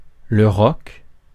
Ääntäminen
Ääntäminen France: IPA: /ʁɔk/ Haettu sana löytyi näillä lähdekielillä: ranska Käännöksiä ei löytynyt valitulle kohdekielelle.